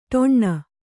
♪ ṭoṇṇa